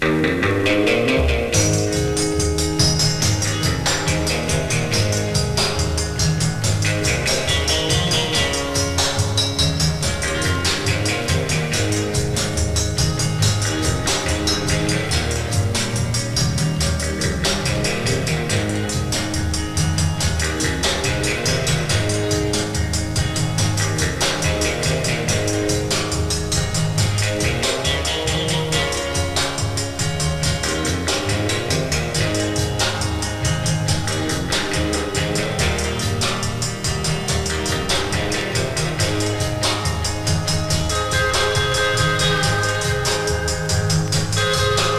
どれもが踊れて、音もやたら良い爽快快活な1枚です。
Rock'N'Roll, Surf, Limbo 　USA　12inchレコード　33rpm　Stereo